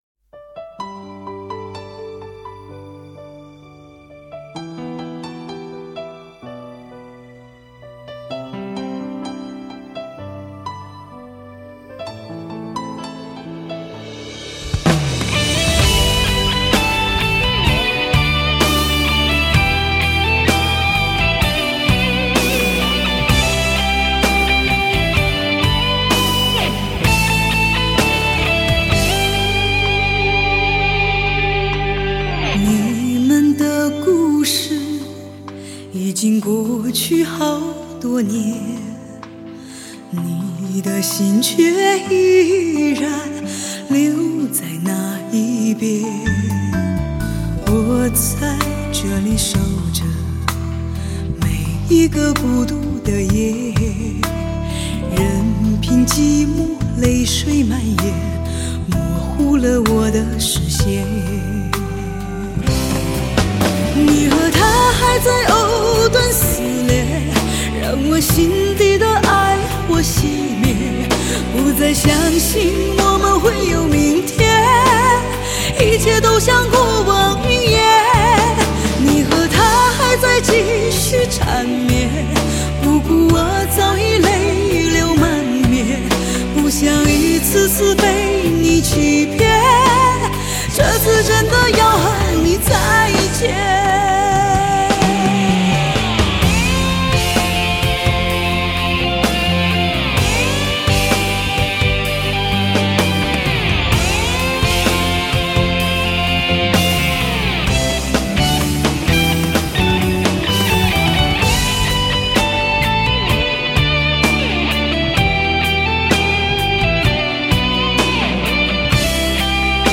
磁性的嗓音吸附你心灵深处
用心的演唱诉说真挚的情感